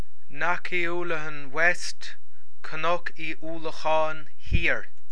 Pronunciation Audio File